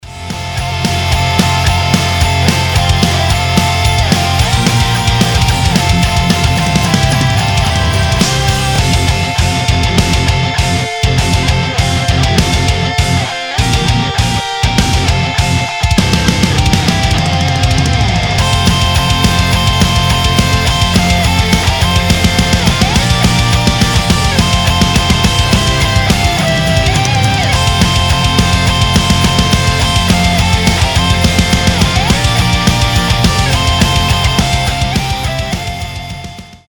громкие
мощные
Metalcore
без слов
быстрые
бодрые
рождественские
метал
Melodic metalcore + знаменитая рождественская песня